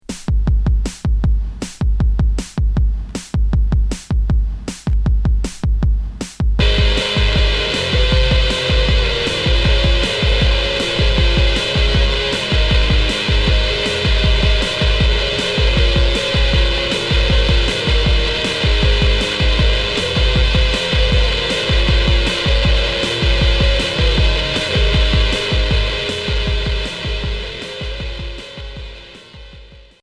Punk synthétique expérimental